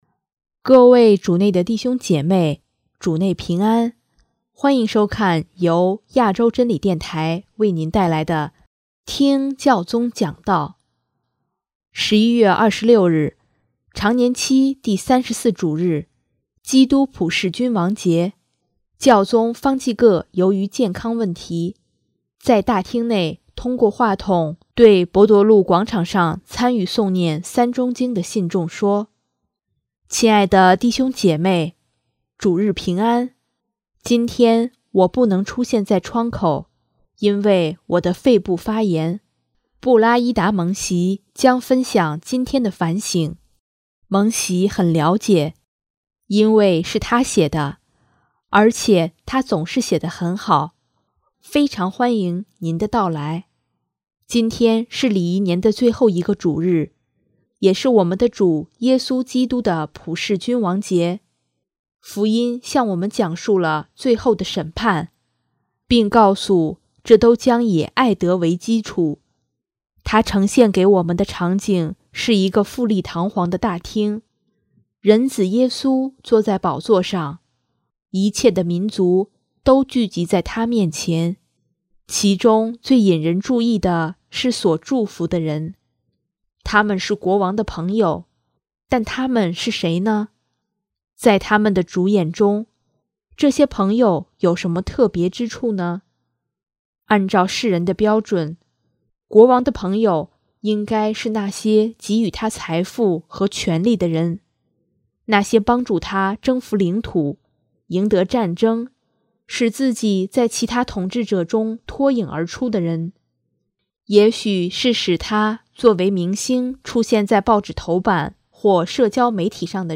11月26日，常年期第三十四主日，基督普世君王节，教宗方济各由于健康问题，在大厅内通过话筒对伯多禄广场上参与诵念《三钟经》的信众说：